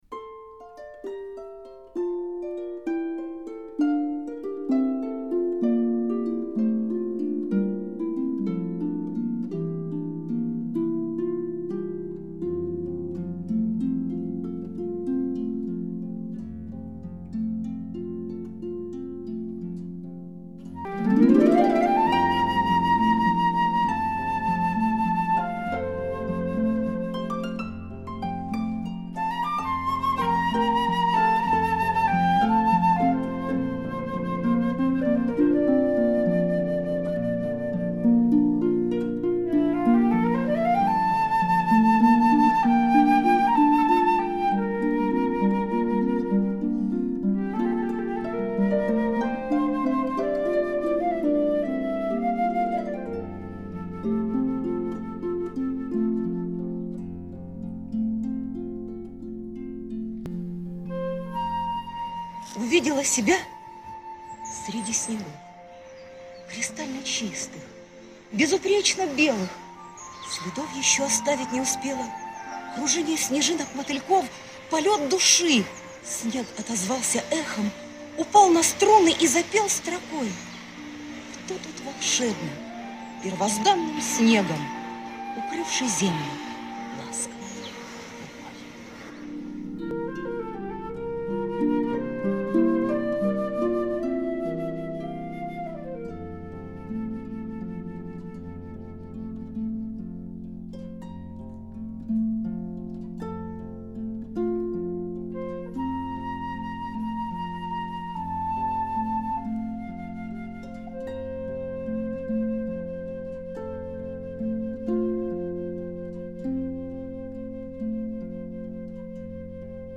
Попробовали прямо на улице снять на телефон, потом еле удалось повернуть видео на 90 градусов.